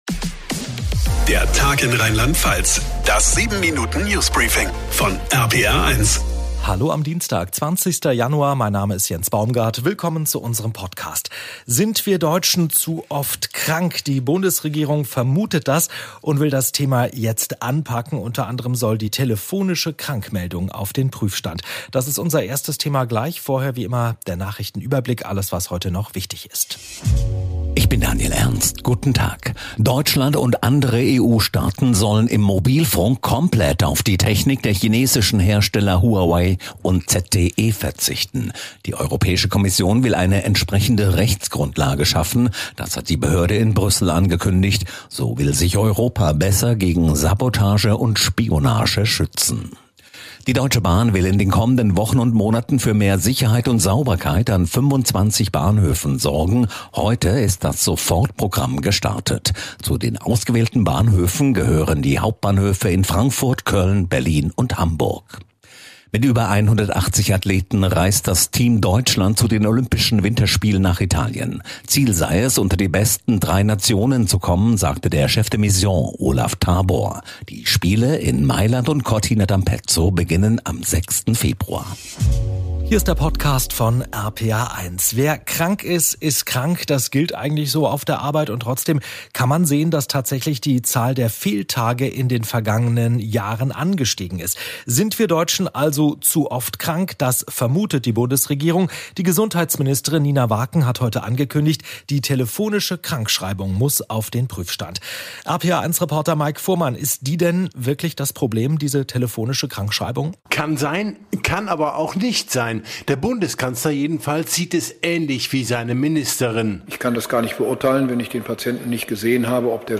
Das 7-Minuten News Briefing von RPR1.